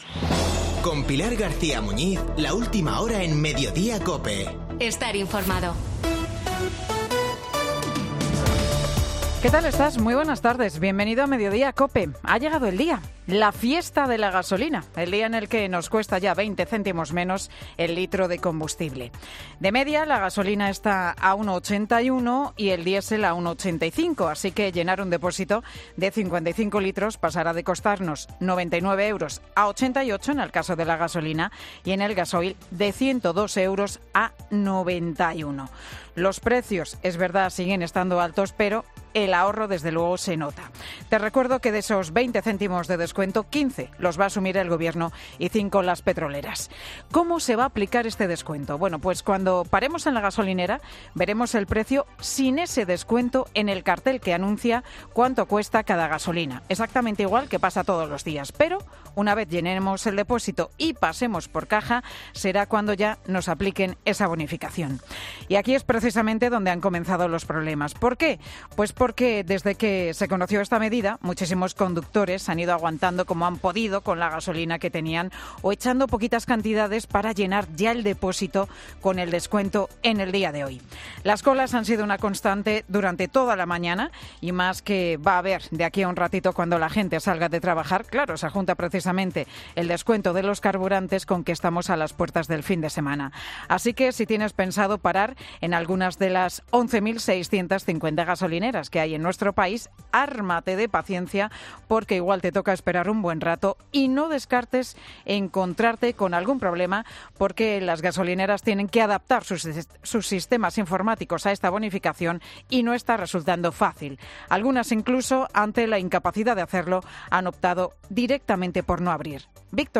El monólogo de Pilar García Muñiz, en Mediodía COPE